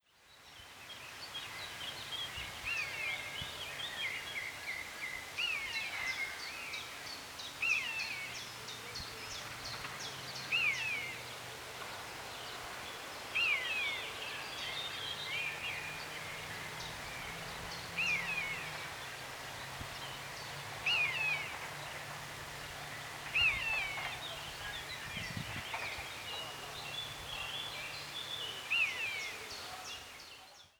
Miñato común
Buteo buteo
Canto